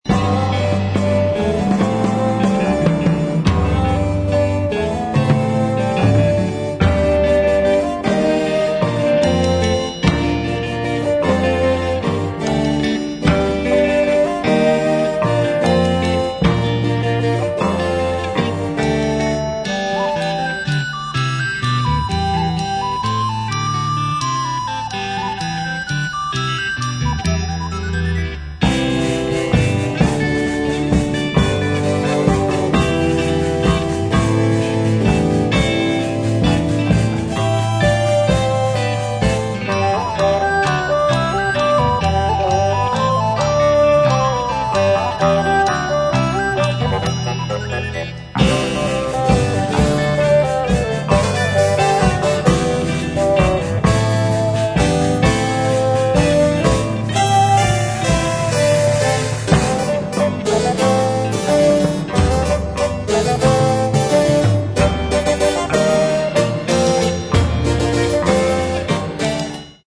Stereo, 1:09, 64 Khz, (file size: 546 Kb).